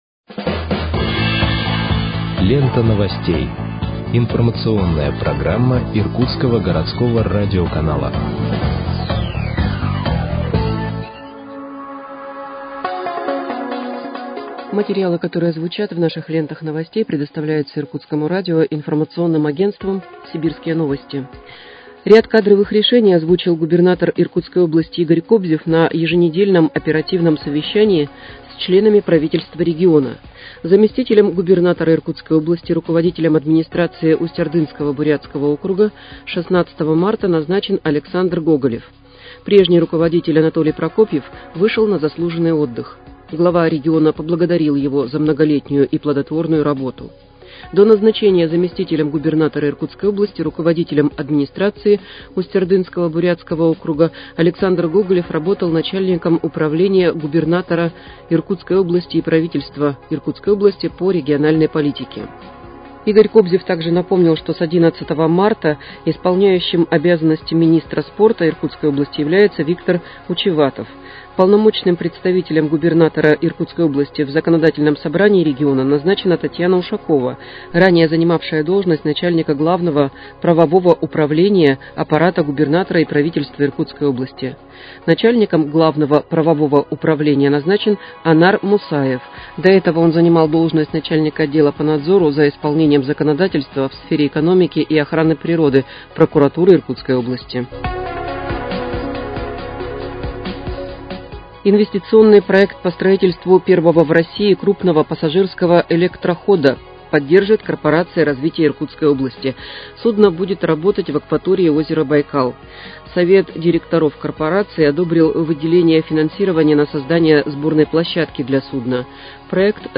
Выпуск новостей в подкастах газеты «Иркутск» от 19.03.2026 № 1